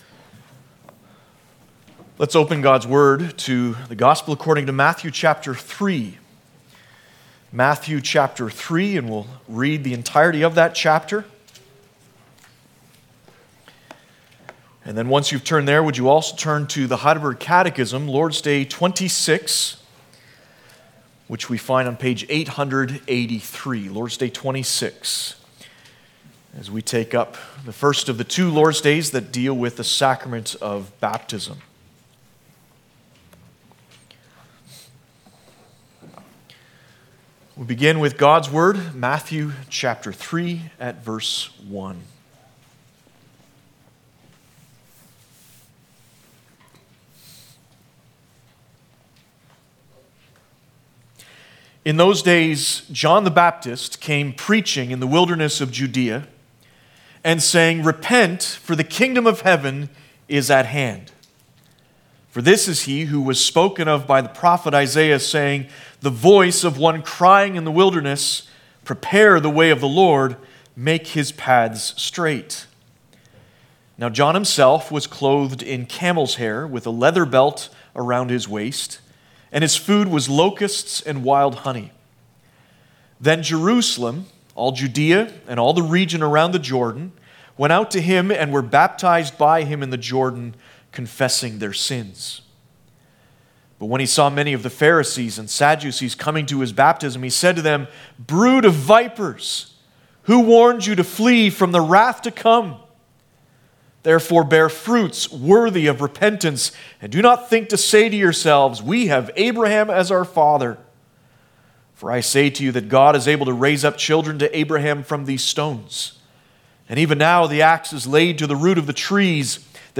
Passage: Matthew 3 Service Type: Sunday Afternoon